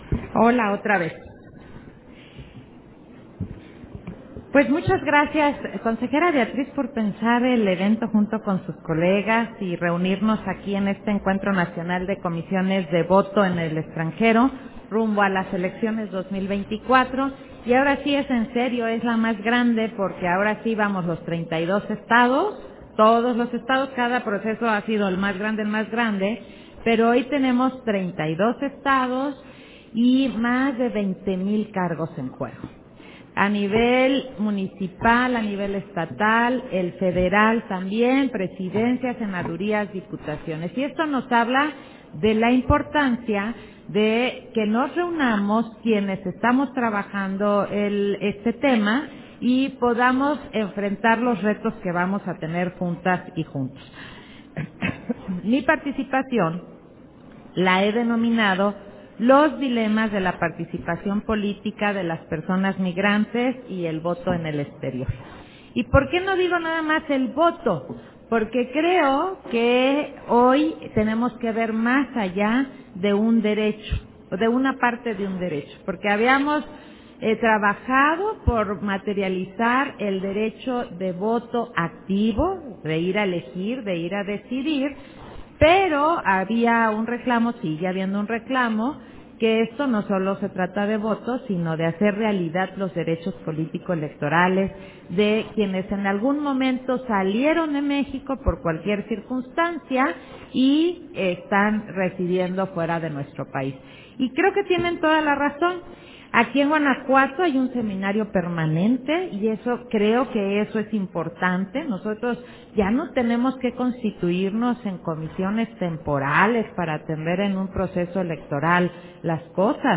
061023_AUDIO_CONFERENCIA-INAUGURAL-CONSEJERA-ZAVALA - Central Electoral